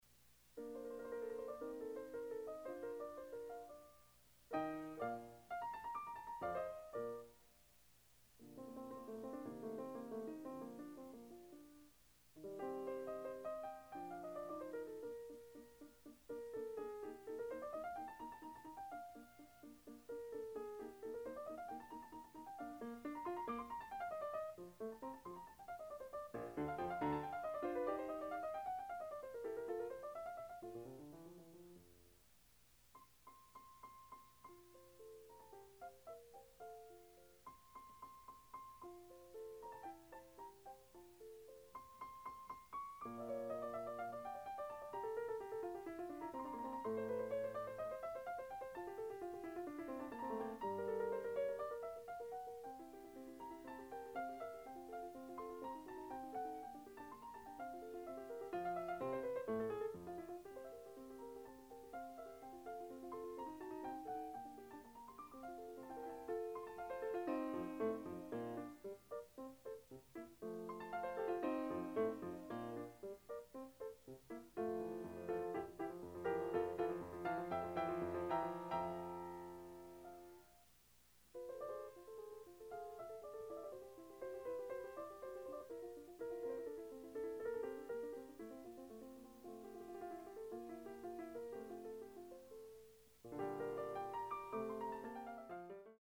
SoloistKoroliov, Evgeni 1949-
Additional Date(s)Recorded September 21, 1973 in the Ed Landreth Hall, Texas Christian University, Fort Worth, Texas
Sonatas (Piano)
Short audio samples from performance